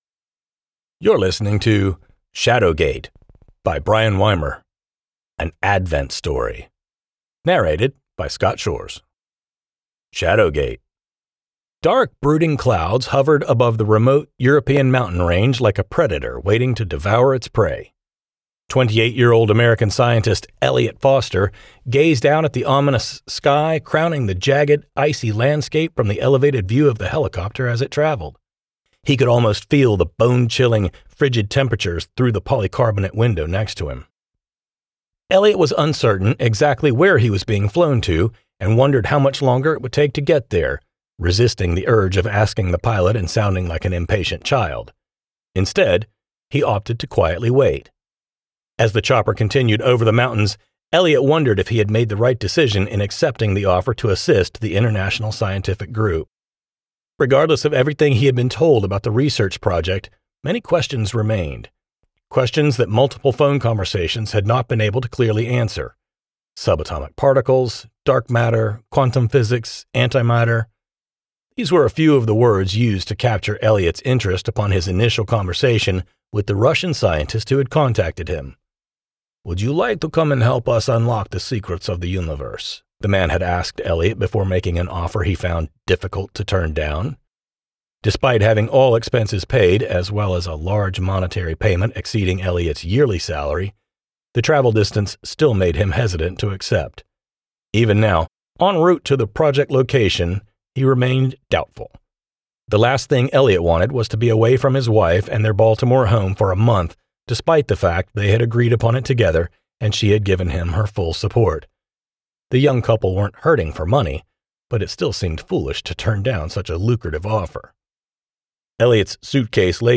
Shadowgate-audiobook.mp3